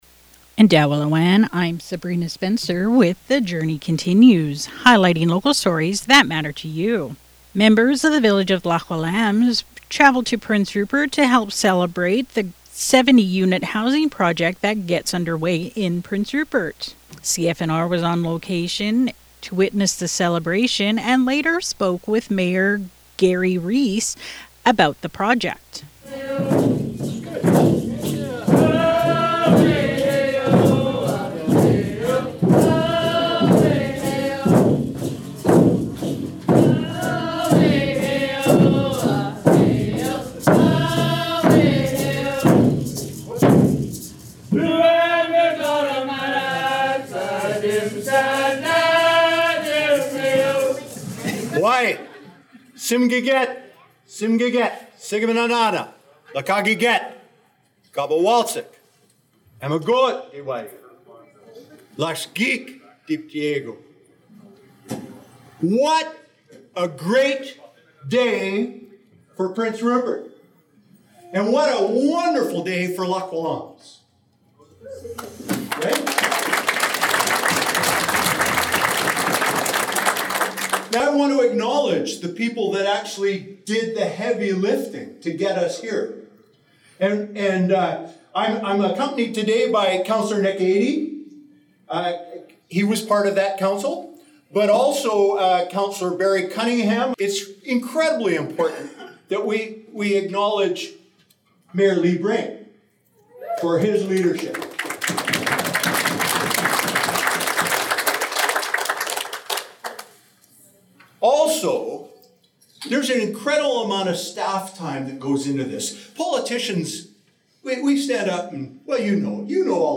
Listen to the speech Prince Rupert Mayor Herb Pond made at the celebration and CFNR’s interview with Lax Kw’alaams Mayor Garry Reece below: